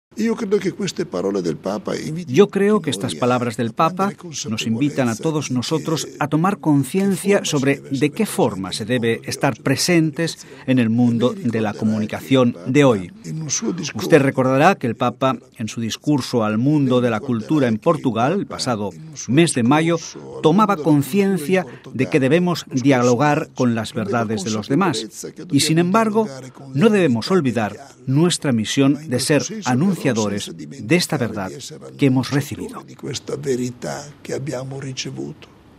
Estas fueron las palabras del presidente del Pontificio Consejo para las Comunicaciones Sociales, Mons. Claudio Maria Celli, que ha presentado hoy este documento pontificio: